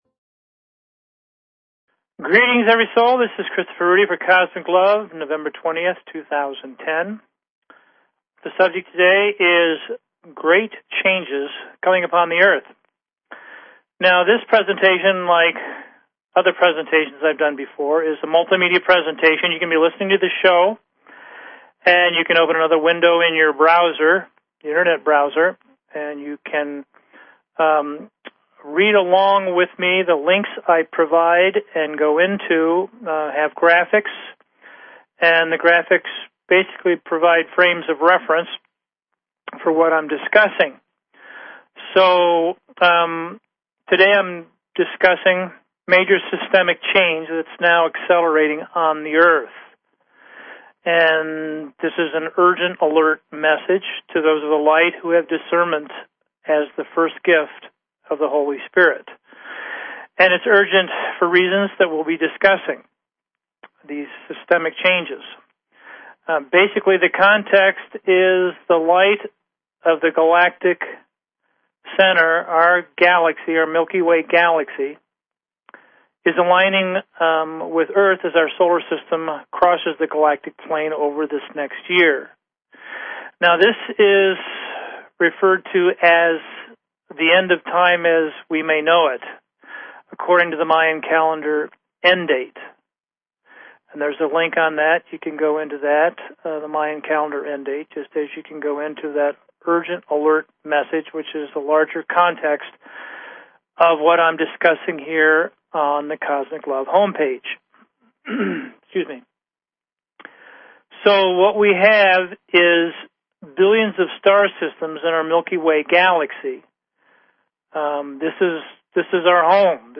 Talk Show Episode, Audio Podcast, Cosmic_LOVE and Courtesy of BBS Radio on , show guests , about , categorized as